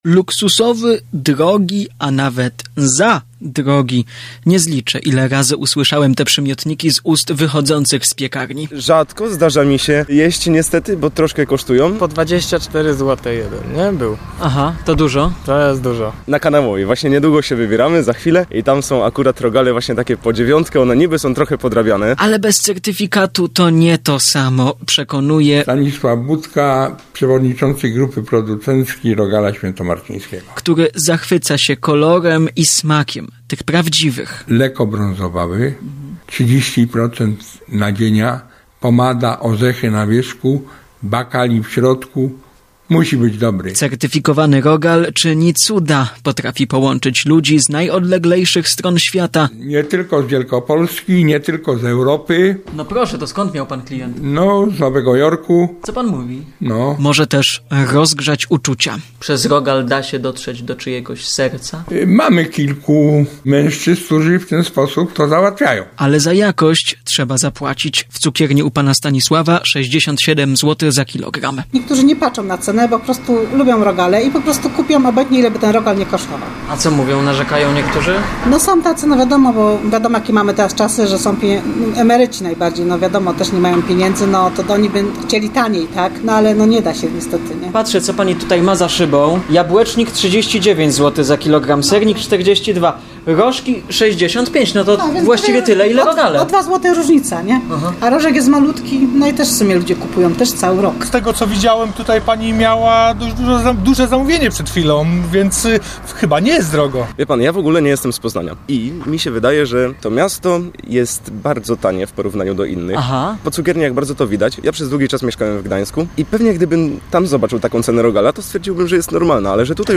– żali się jeden z zapytanych poznaniaków.
– komentuje doświadczony cukiernik.
– przyznaje student.
– puentuje ekspedientka w piekarki.